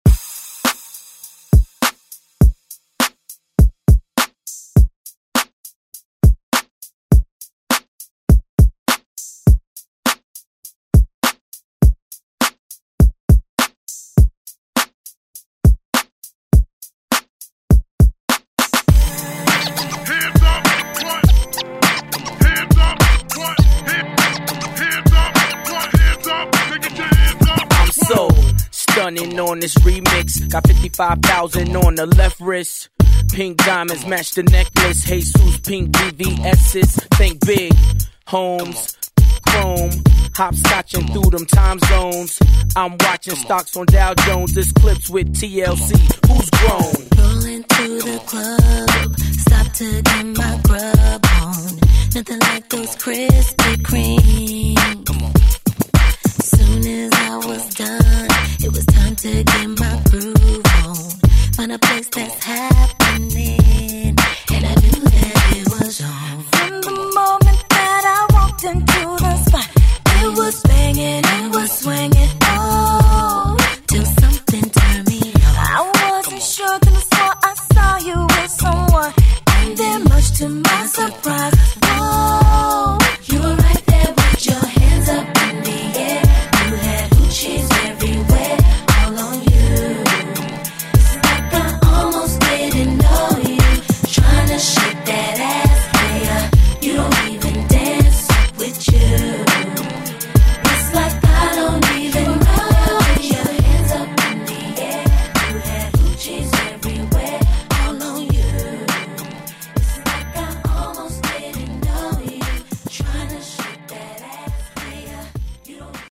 Throwback Electronic Pop Rock Music
Genre: 90's
Clean BPM: 105 Time